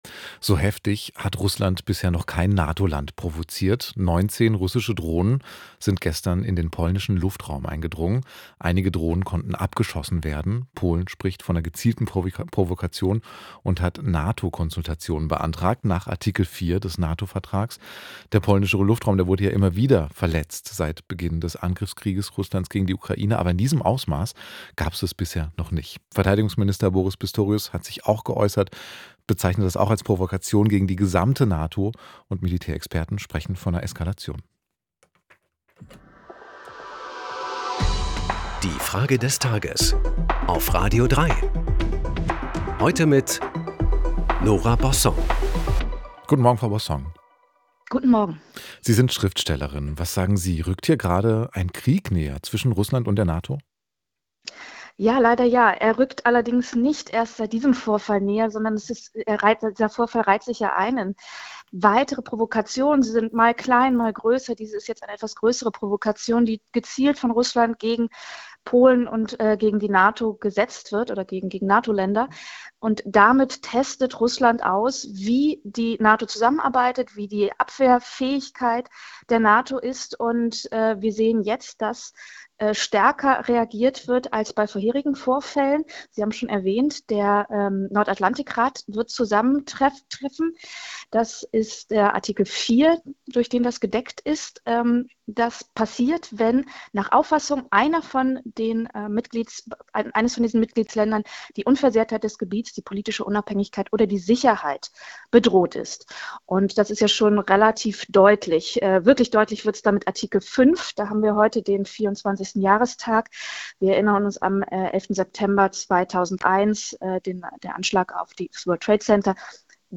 Frage des Tages an die Schriftstellerin Nora Bossong.